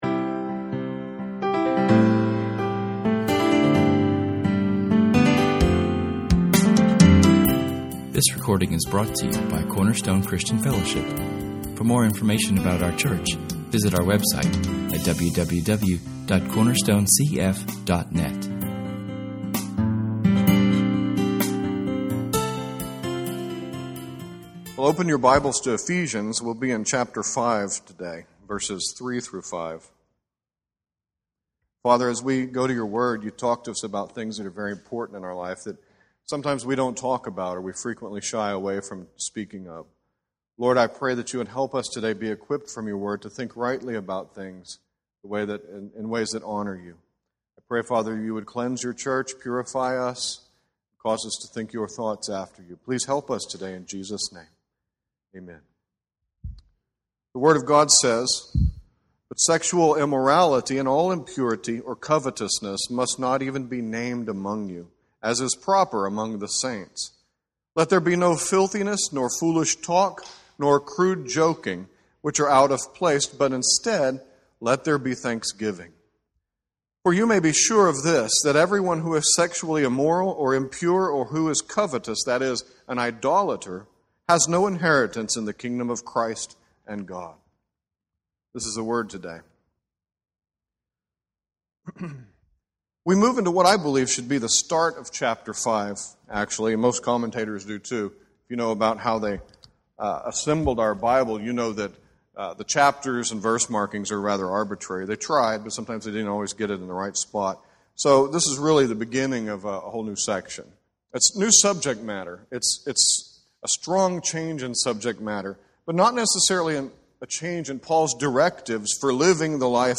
How should we think, and how should we NOT think, about sexuality? In this sermon we learn about keeping one’s self holy with regard to sexuality.